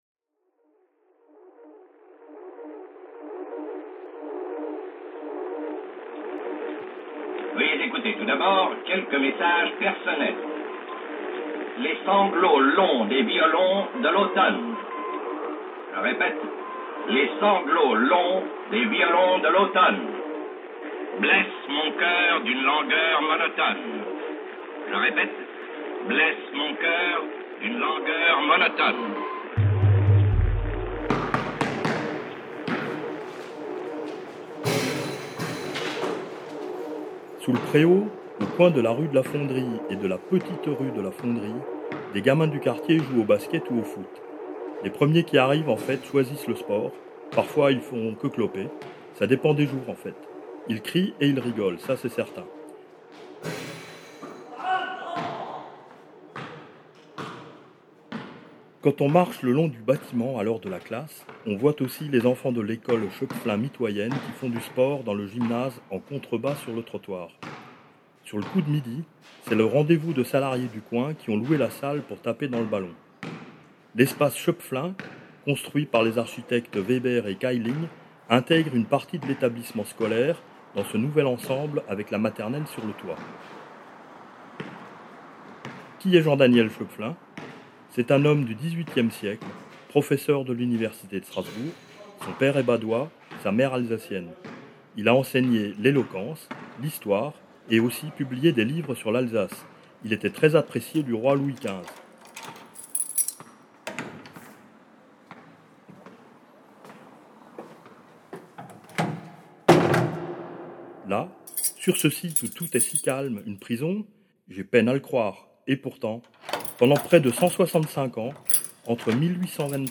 À écouter de préférence avec un bon casque